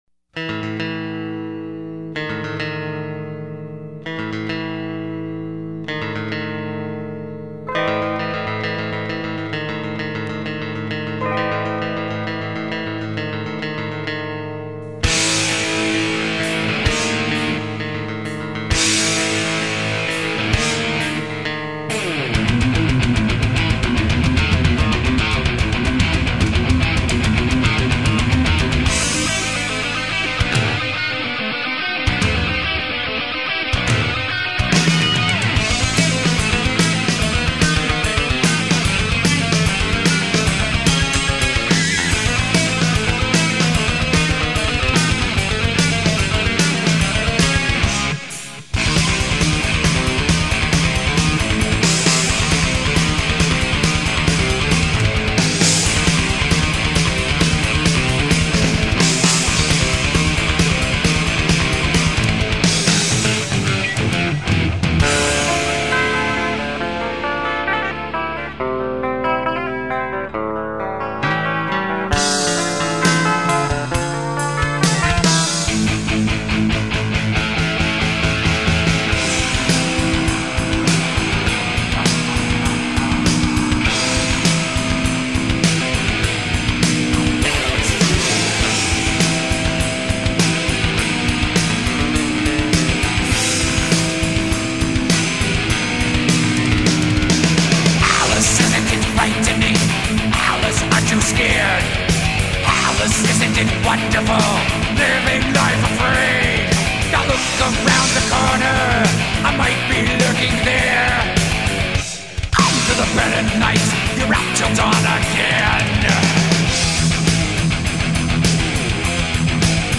سبک:ترش متال